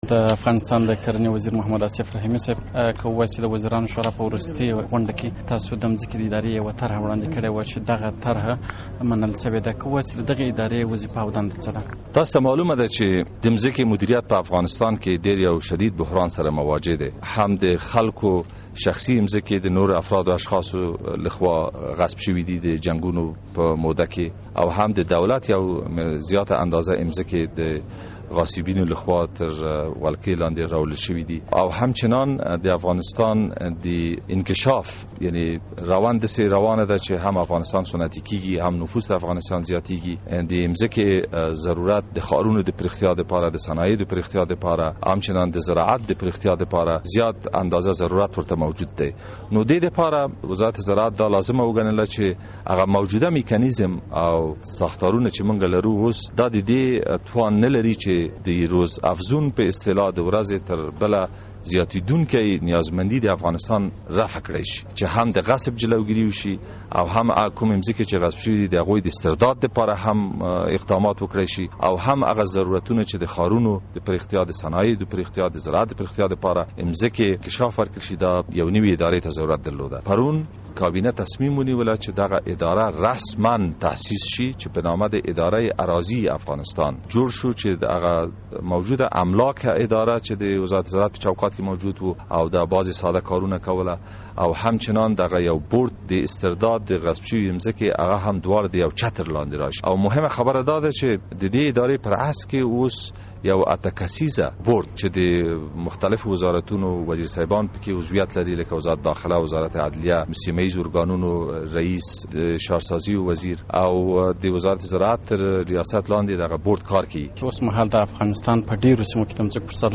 د افغانستان د کرني وزیر محمد اصف رحیمي له ازادۍ راډیو سره ځانګړې مرکه